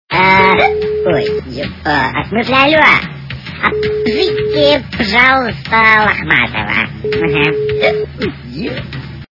При прослушивании голос Mасяни - А позовите, пожалуйста, лохматого качество понижено и присутствуют гудки.